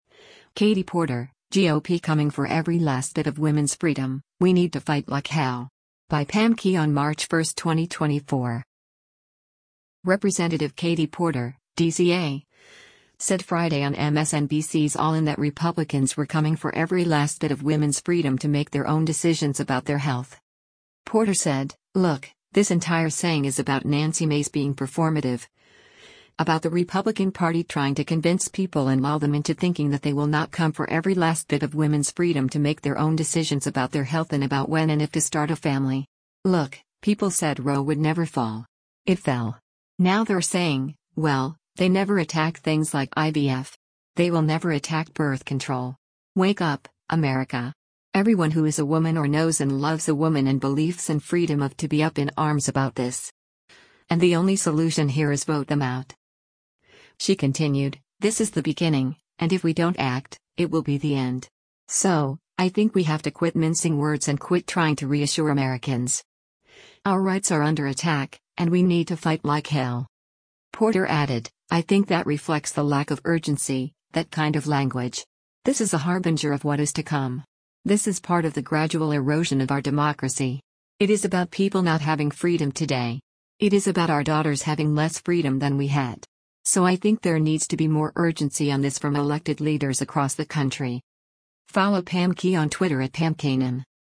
Representative Katie Porter (D-CA) said Friday on MSNBC’s “All In” that Republicans were coming “for every last bit of women’s freedom to make their own decisions about their health.”